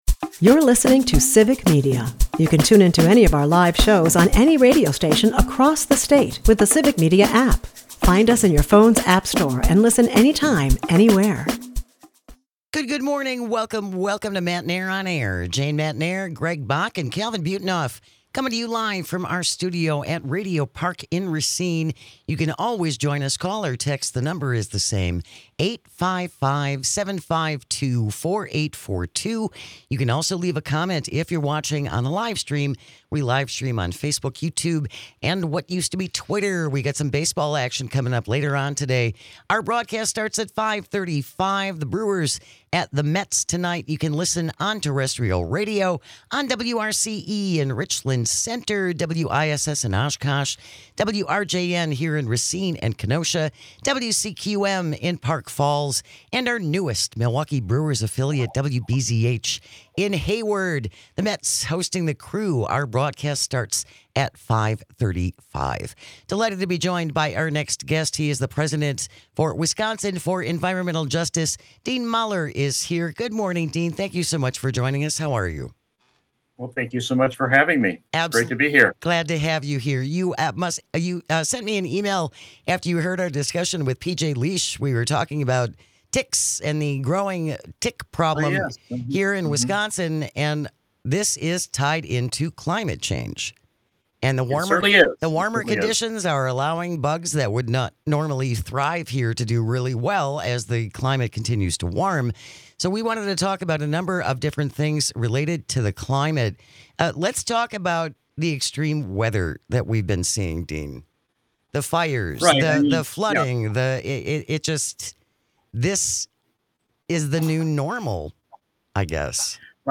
State Senator Kelda Roys (D-26) calls into to give her opinions on the tentative budget and why it isn't as rosy as some are portraying. To honor the start of a new month, we give you This Shouldn't Be A Thing - You Dirty Rat Edition Matenaer On Air is a part of the Civic Media radio network and airs Monday through Friday from 9 -11 am across the state.